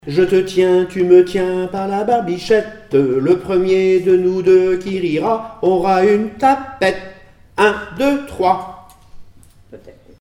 formulette enfantine : risette
Comptines et formulettes enfantines
Pièce musicale inédite